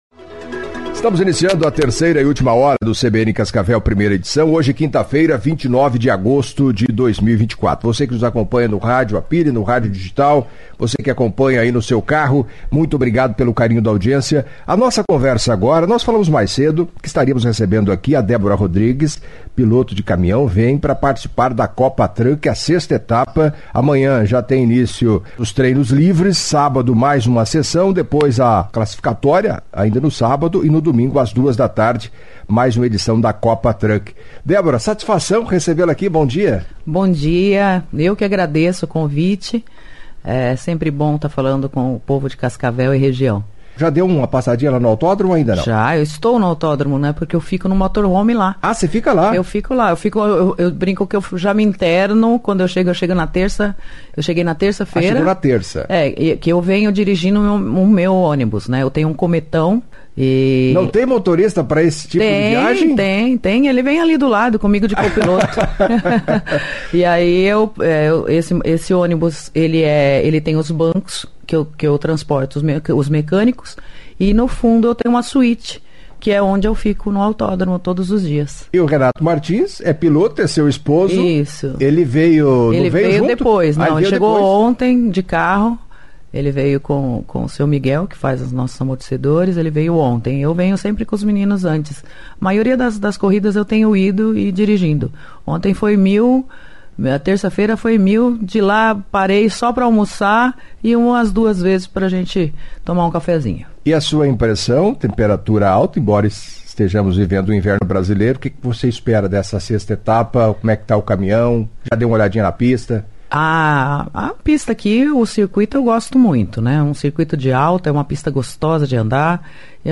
Em entrevista à CBN Cascavel nesta quinta-feira (29) Debora Rodrigues, piloto da Copa Truck, relembrou momentos de sua história de vida, emocionando alguns ouvintes.